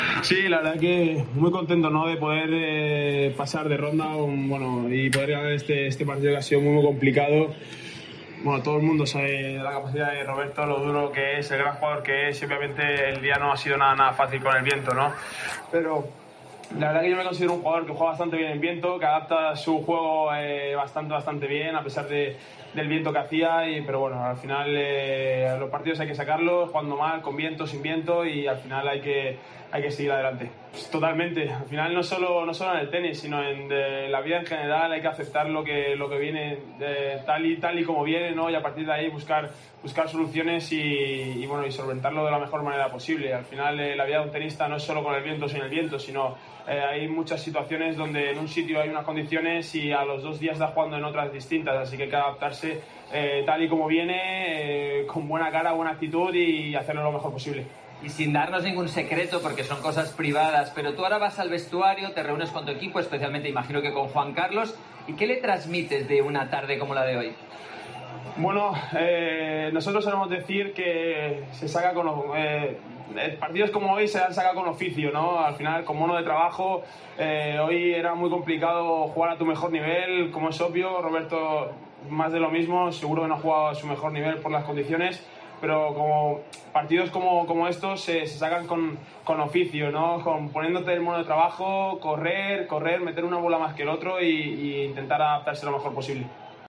Declaraciones del tenista murciano al final del partido en Teledeporte